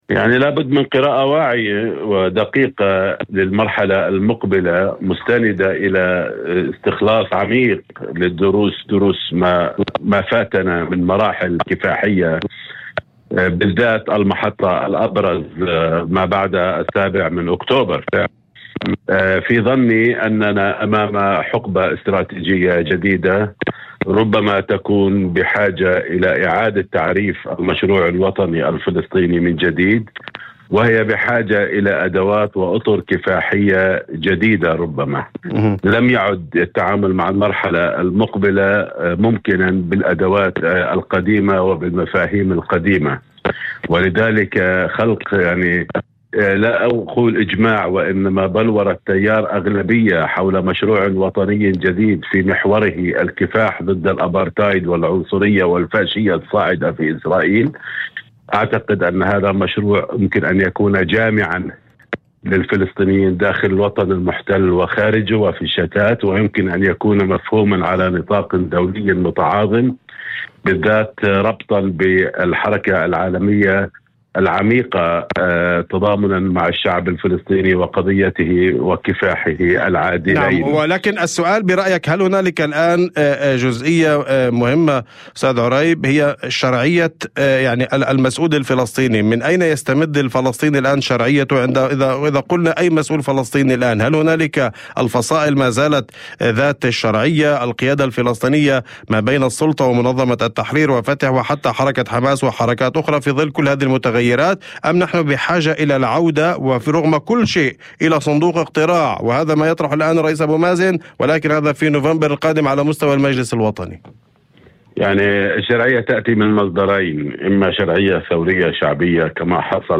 وأضاف في مداخلة هاتفية ضمن برنامج "أول خبر" على إذاعة الشمس، أن الفلسطينيين يقفون اليوم أمام حقبة استراتيجية جديدة قد تستدعي إعادة تعريف المشروع الوطني الفلسطيني برمته.